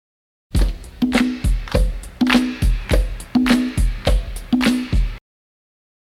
Heres my acapella and beat